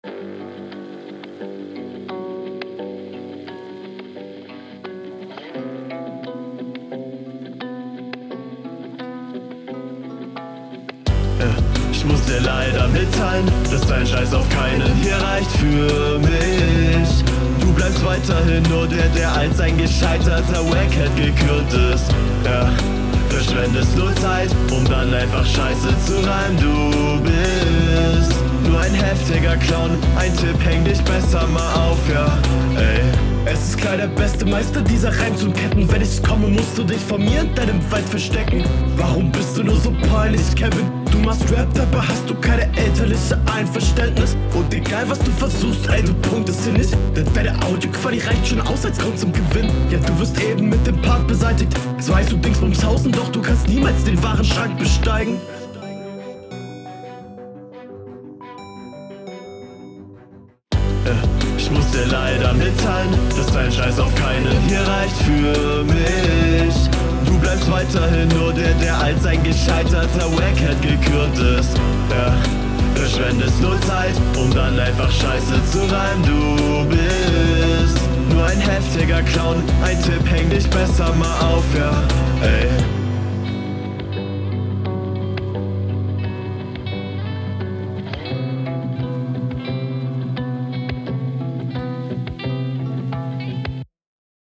lass das autotune weg, rest dope